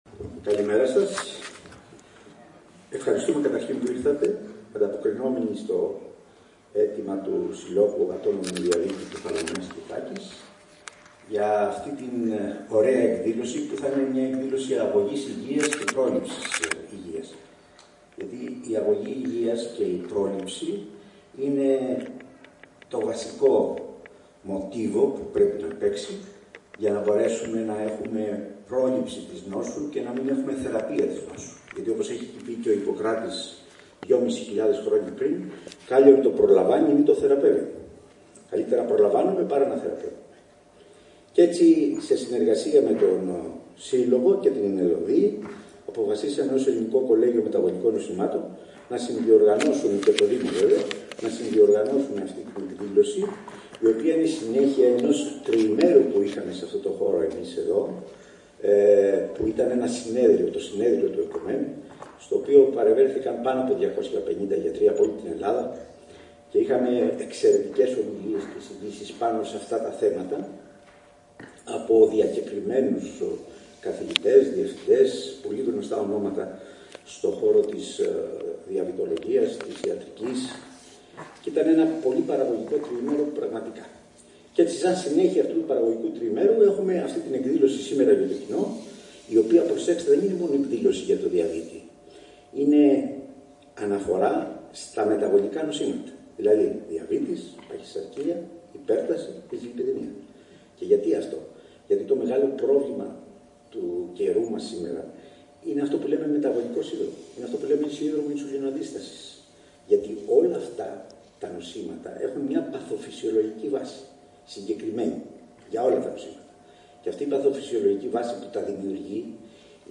Ο Σύλλογος ατόμων με διαβήτη και φίλων Κεφαλονιάς και Ιθάκης, το Ε.ΚΟ.ΜΕ.Ν (Ελληνικό Κολλέγιο Μεταβολικών Νοσημάτων), υπό την αιγίδα της ΕΛ.Ο.ΔΙ (Ελληνικής Ομοσπονδίας για το Διαβήτη) και το Δήμο Αργοστολίου διοργάνωσαν σήμερα Κυριακή στο Δημοτικό Θέατρο “Ο ΚΕΦΑΛΟΣ” Ημερίδα με θέμα: “Τα μεταβολικά νοσήματα (Διαβήτης, Παχυσαρκία, Υπέρταση, Δυσλιπιδαιμία) η μάστιγα υγείας στην εποχή μας: Διάγνωση και Θεραπεία”